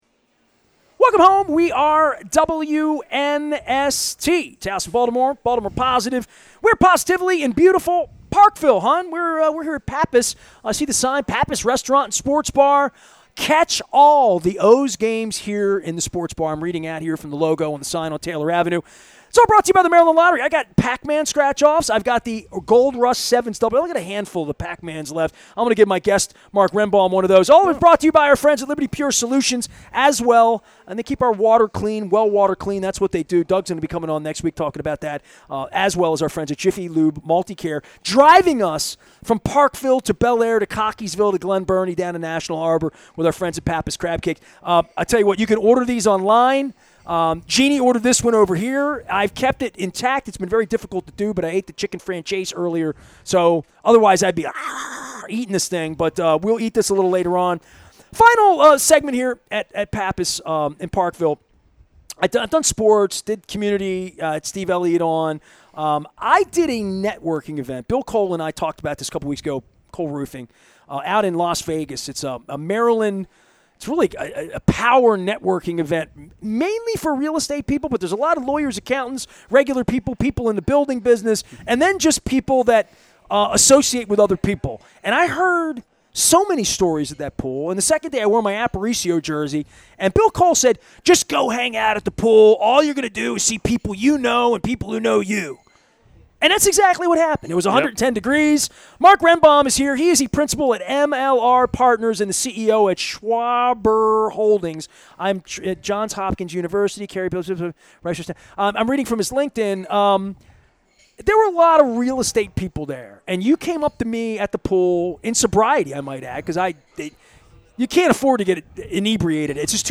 at Pappas in Parkville on the Maryland Crab Cake Tour